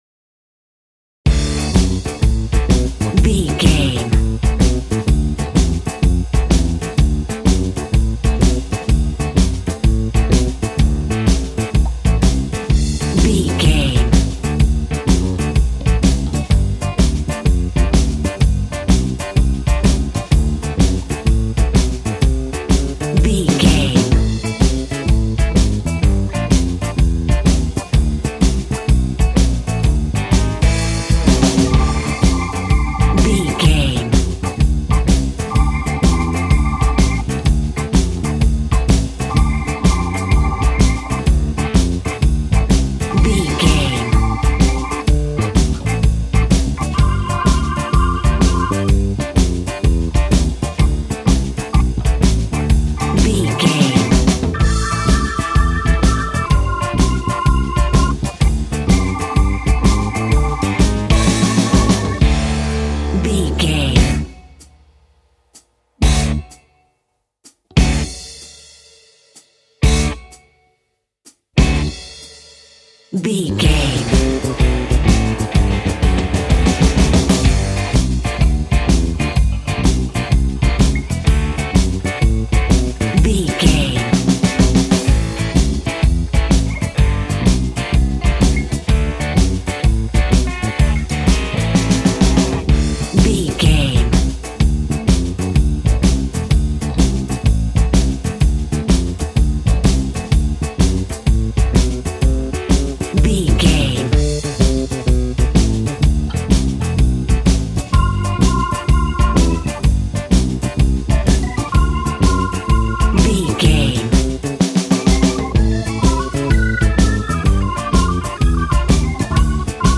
Ionian/Major
D
sad
mournful
bass guitar
electric guitar
electric organ
drums